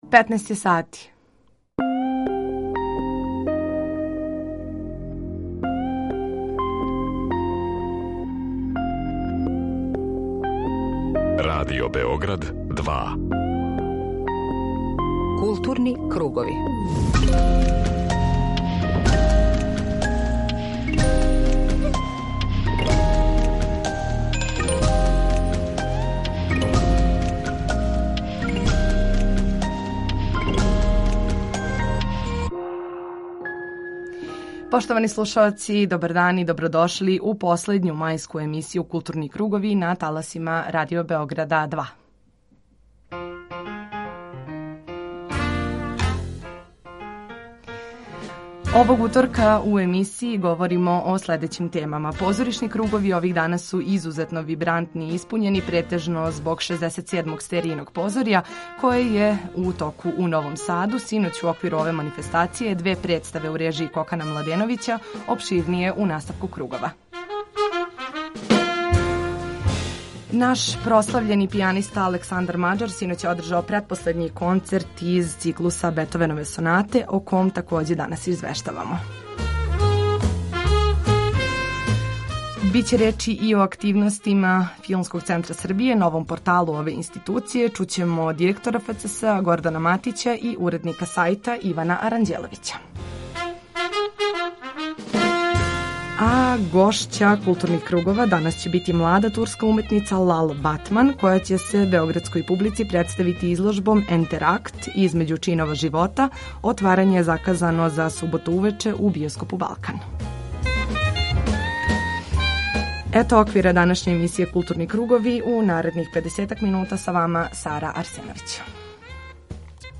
Извештавамо и са конференције за новинаре која је одржана у Филмском центру Србије, а поводом представљања новог интернет портала и резултата постигнутих у 2021. години. Очекује нас и укључење са 67. Стеријиног позорја, које је у Новом Саду у току до 3. јуна.